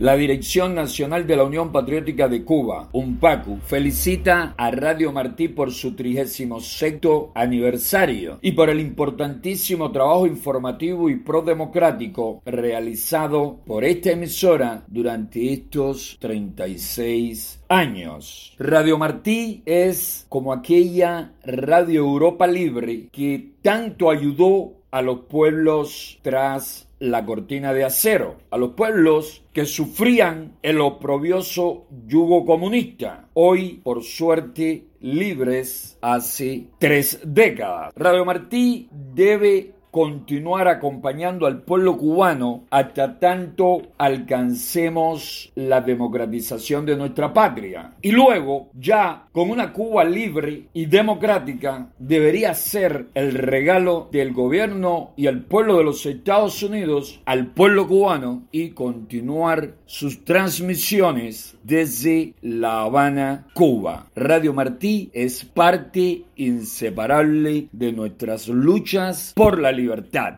El líder de la Unión Patriótica de Cuba (UNPACU), José Daniel Ferrer García, desde Santiago de Cuba, felicitó en su nombre, y en el de la organización que dirige, el cumpleaños 36 de Radio Martí, y agradeció la labor que la emisora ha venido realizando.